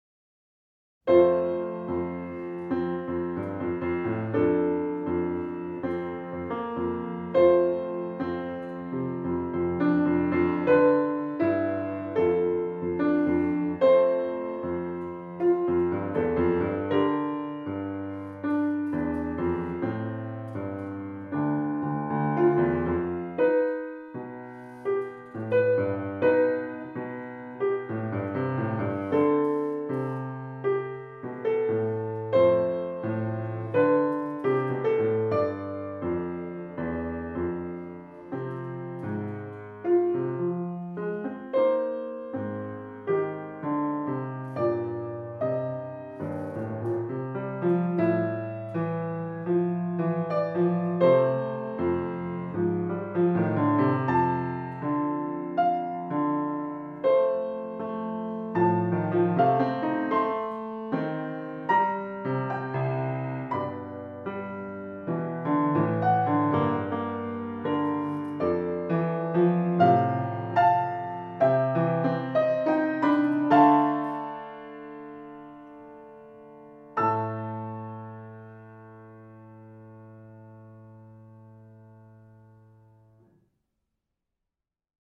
Musik zum Mantra 19 S — naiv
aufmerksam